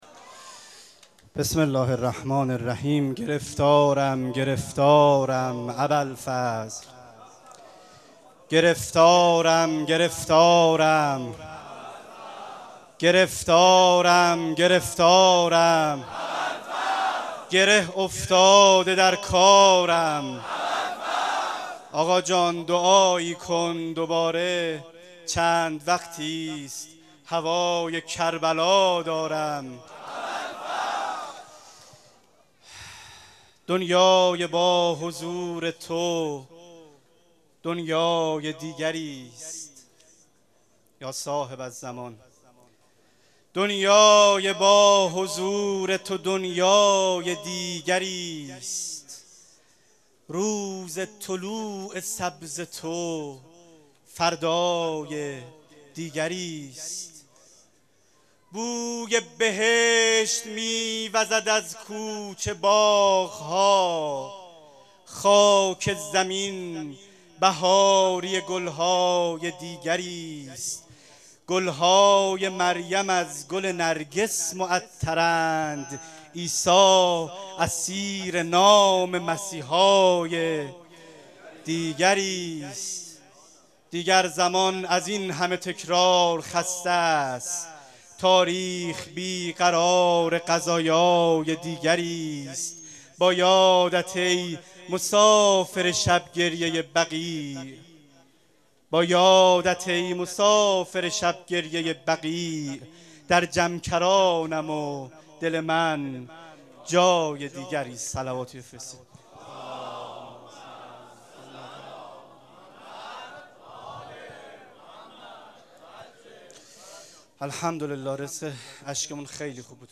شعر خوانی
مناسبت : شب هشتم محرم